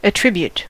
attribute-verb: Wikimedia Commons US English Pronunciations
En-us-attribute-verb.WAV